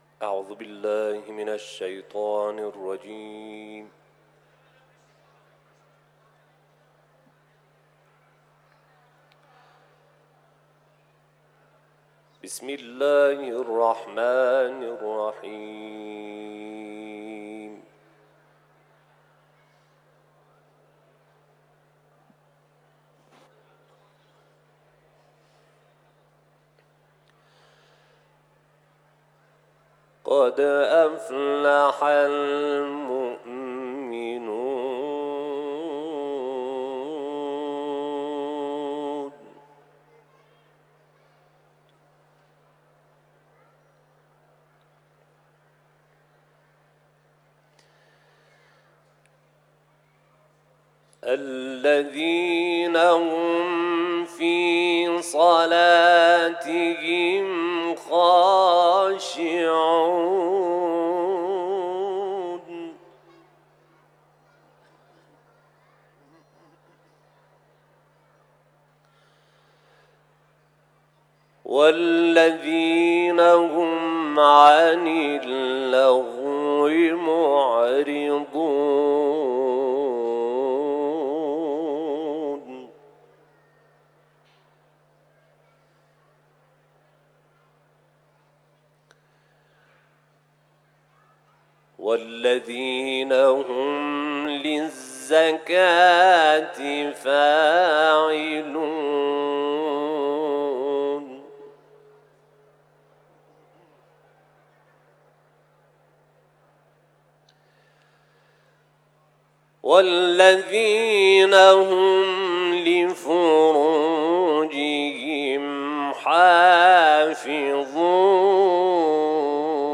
تلاوت
سوره مومنون ، حرم مطهر رضوی